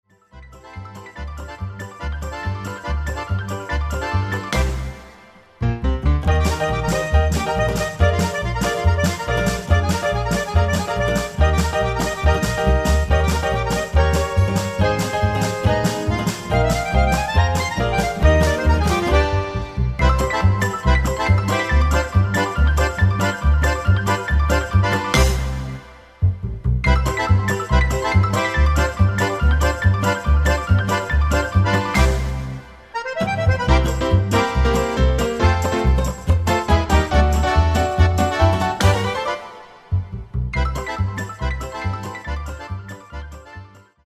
version live